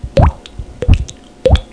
DRIPS.mp3